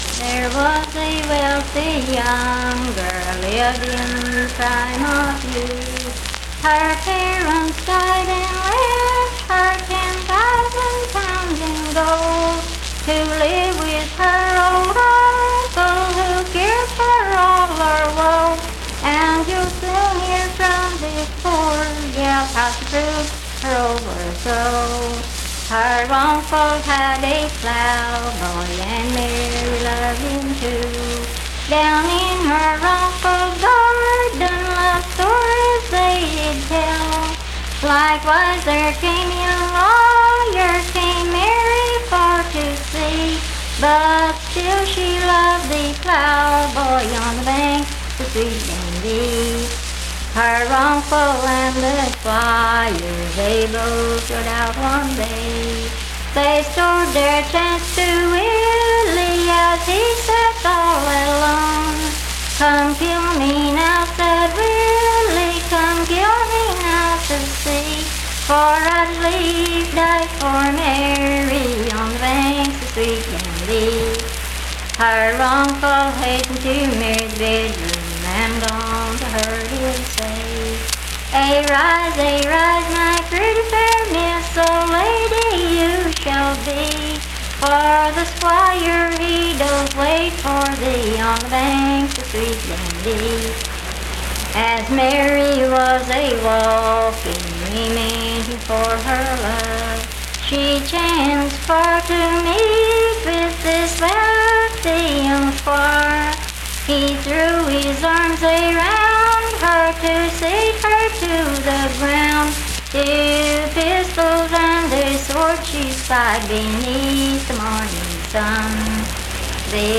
Unaccompanied vocal music
Verse-refrain 8d(4).
Voice (sung)